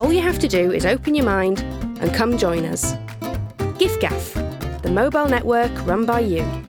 Northern
Commercial, Bright, Upbeat, Conversational